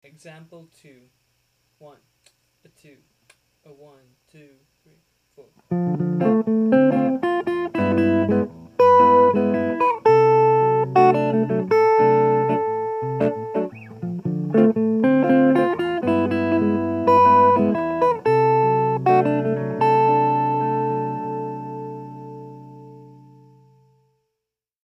For ease of understanding, I've written all the examples as Major II-V-I progressions in the key of C Major.
(Listen) - Here I use repeated notes to create interest in the ascending F Major arpeggio and the for the next two triads, I utilized open voiced triads yet again.